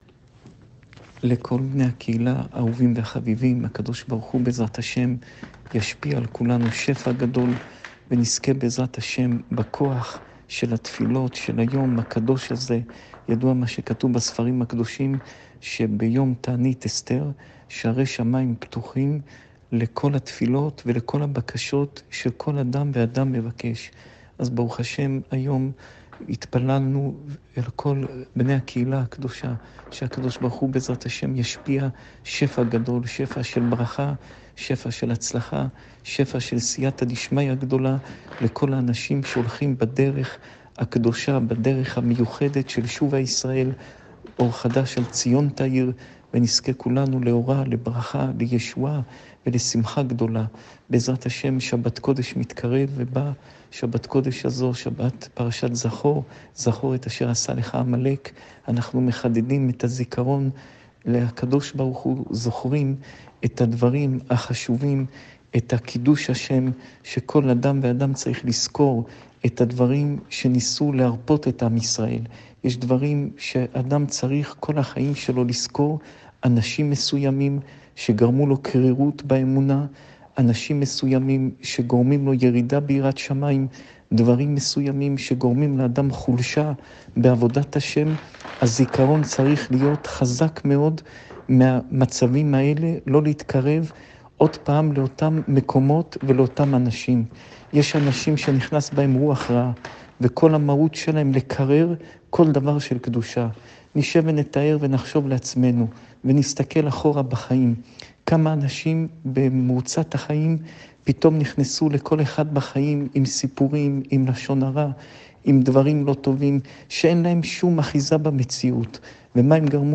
שעורי תורה מפי הרב יאשיהו יוסף פינטו
שעור תורה מפי הרב פינטו